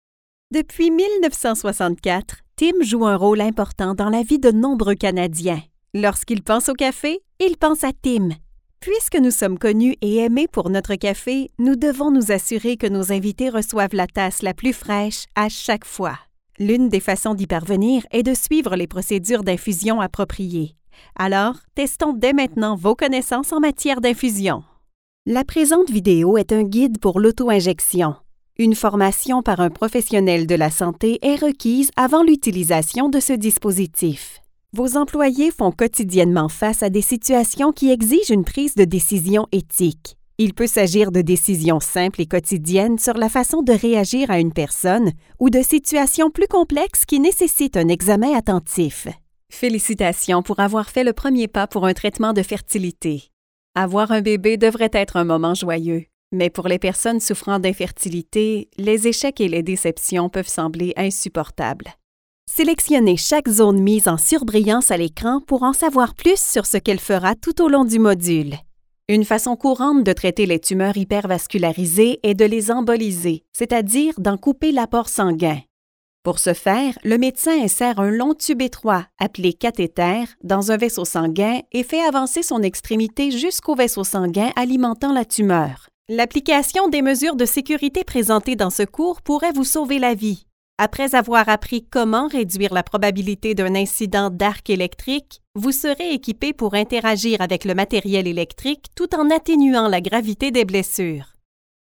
Natural, Versátil, Amable, Accesible, Seguro
E-learning